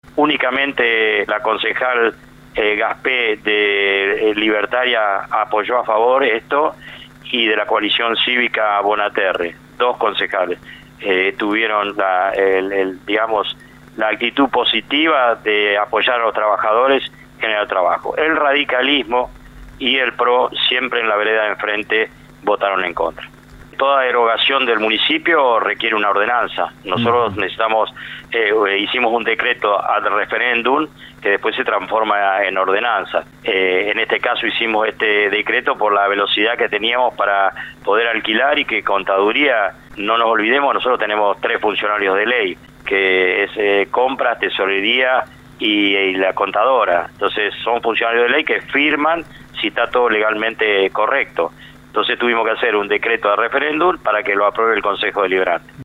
El intendente de Coronel Suárez Ricardo Moccero habló con LU 24 sobre la propuesta impulsada luego del cierre de la empresa DASS, por lo que el oficialismo decidió presentar una propuesta para crear un taller Municipal textil, pese a ser rechazada por los Concejales de la oposición, consiguieron los votos para que se apruebe.